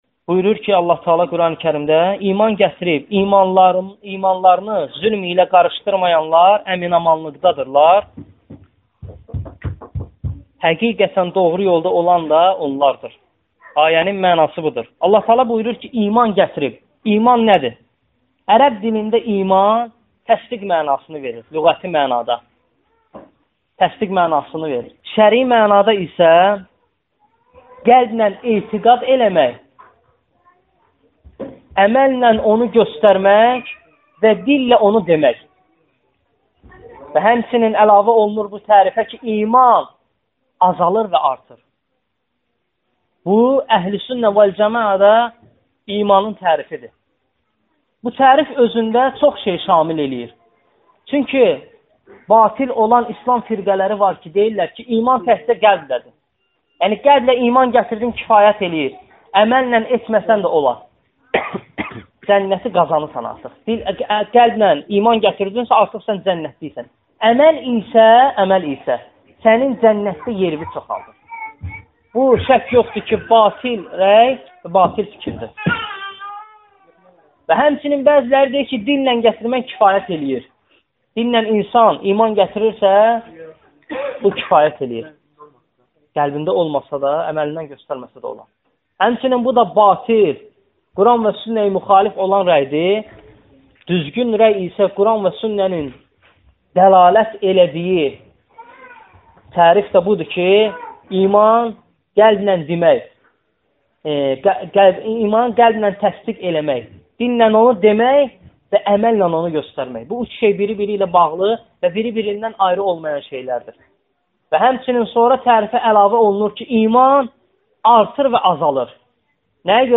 Dərslərdən alıntılar – 22 parça